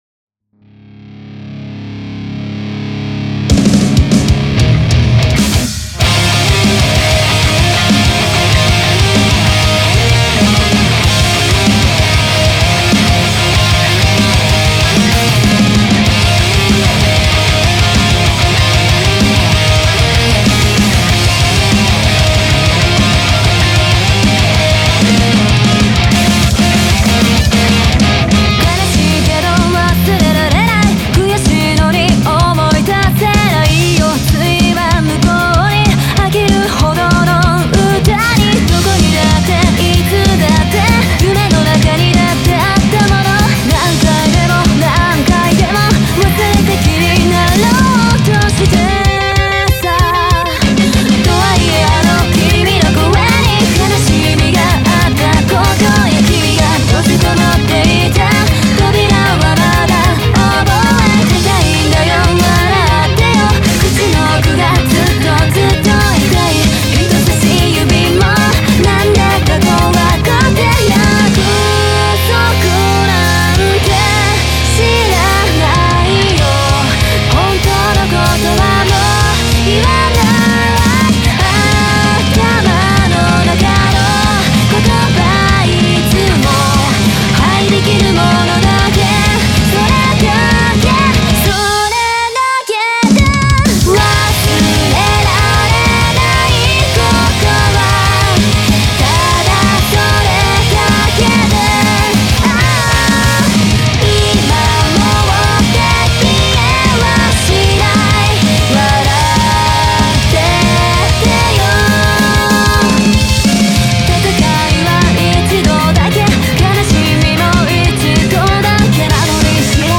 スクリーム無しヴァージョンを公開しました。